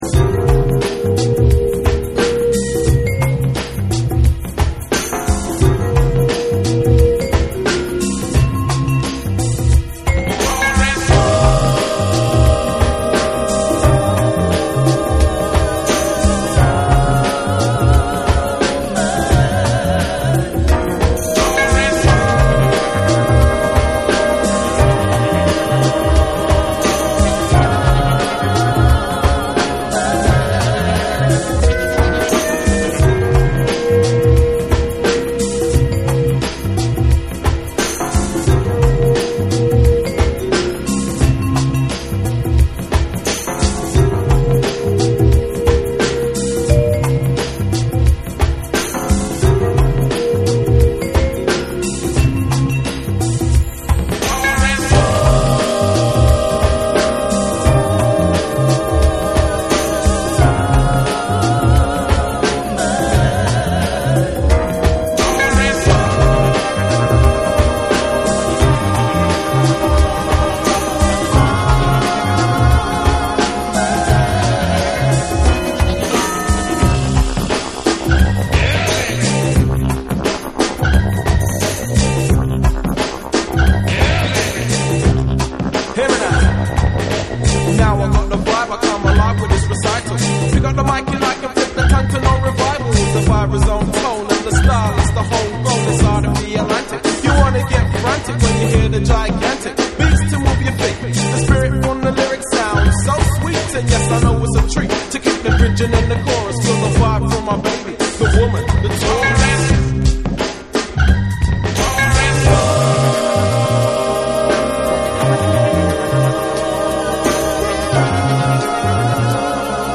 CLUB / BREAKBEATS